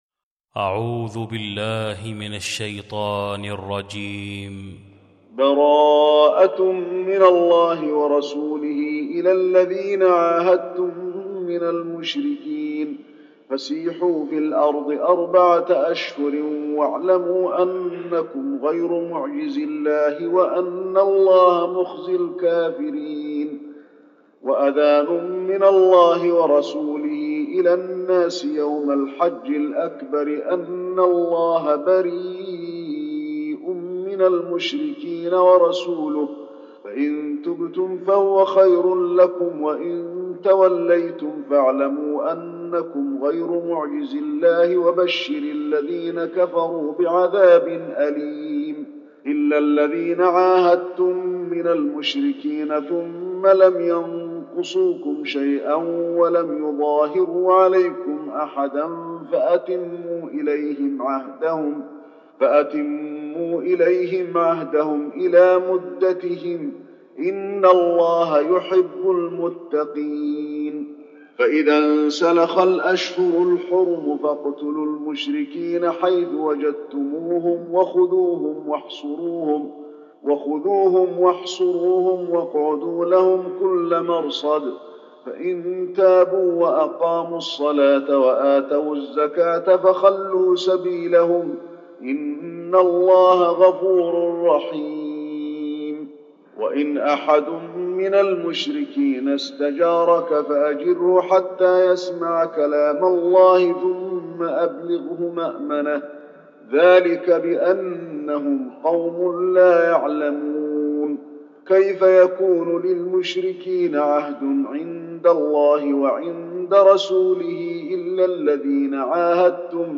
المكان: المسجد النبوي التوبة